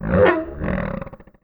MONSTERS_CREATURES
MONSTER_Growl_Hurt_mono.wav